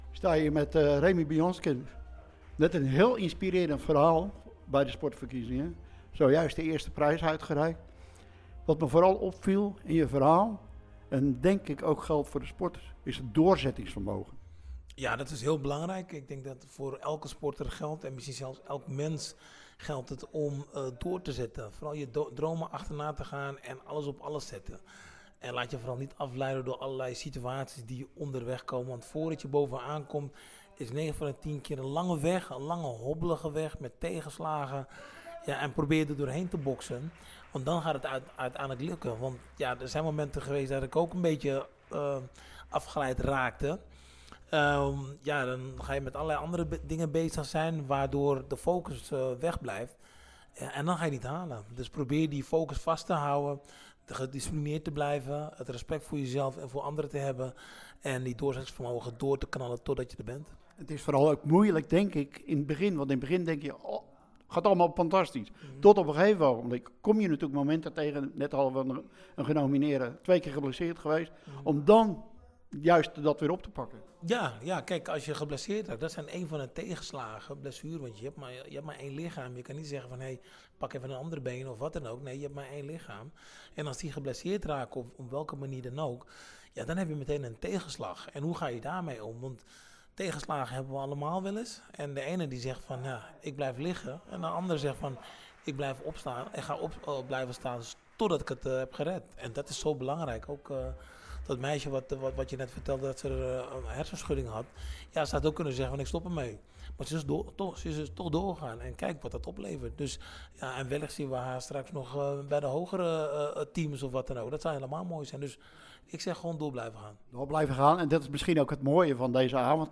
Teylingen – Tijdens het Sportgala Teylingen zijn donderdagavond de jaarlijkse sportprijzen uitgereikt.
Hieronder het radio-interview met Remy Bonjasky: